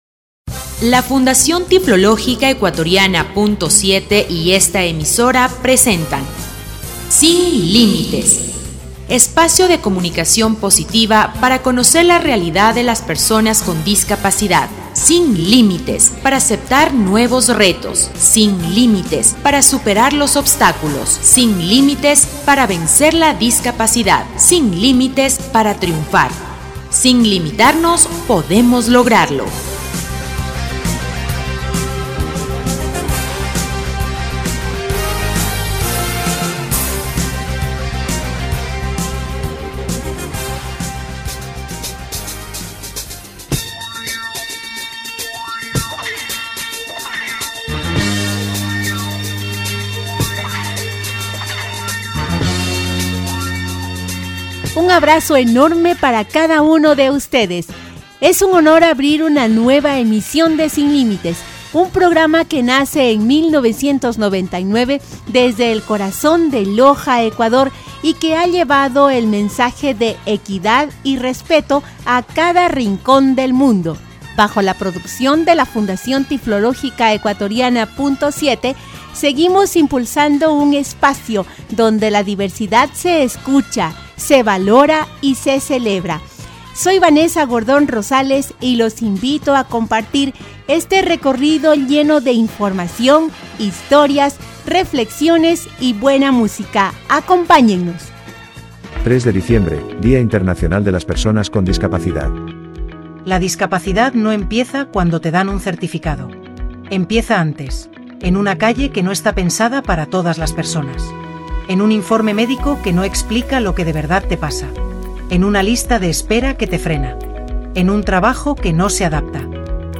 Espacio de comunicación positiva para conocer la realidad de las personas con discapacidad, disfruta de una nueva edición del programa radial «Sin Límites».